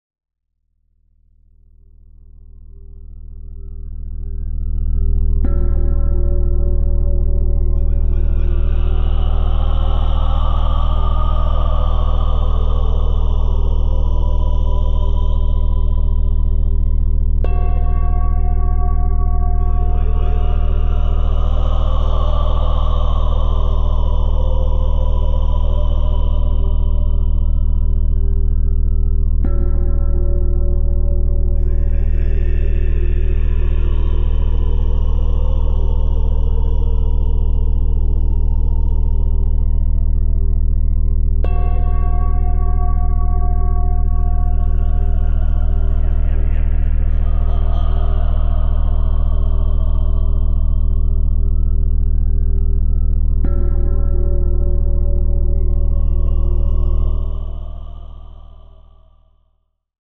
Preview Gamma in C